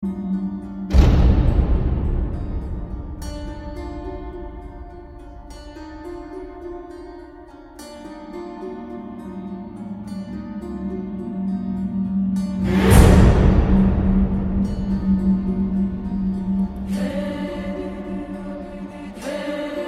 Kategorileri : Message Tones